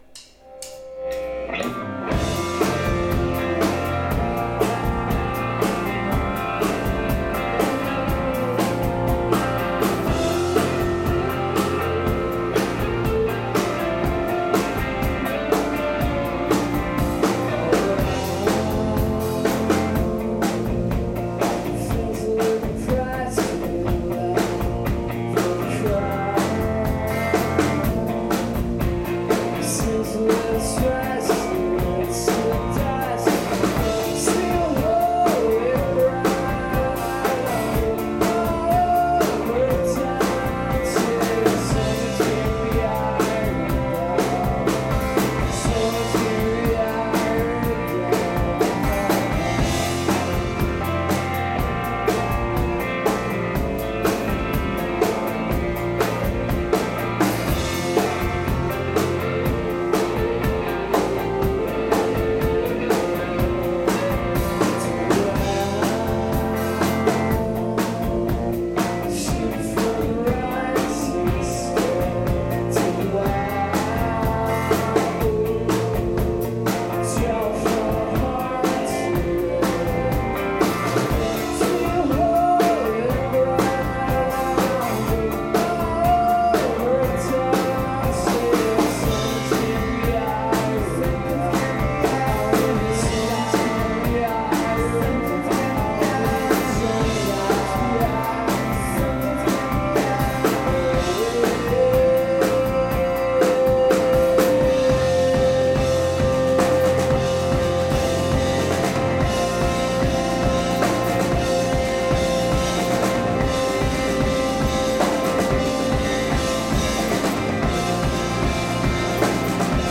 northstar philadelphia July 18 2001